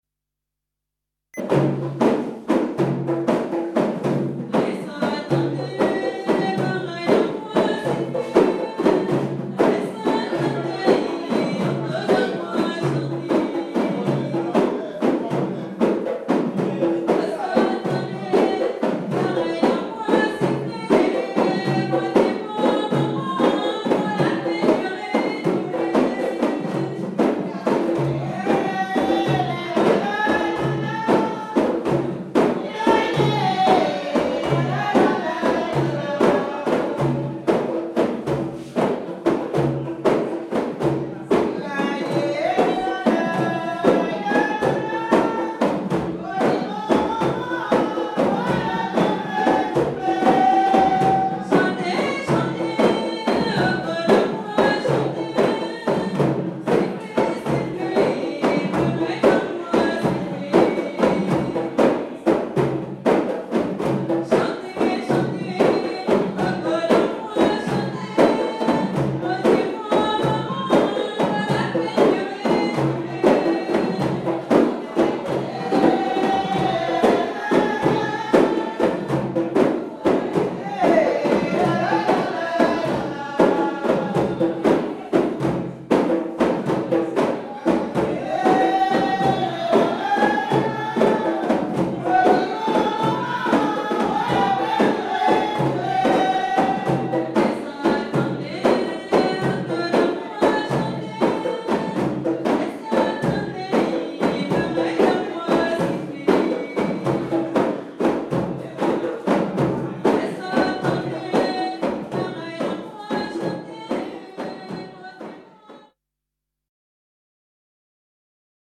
danse : grajé (créole)
Pièce musicale inédite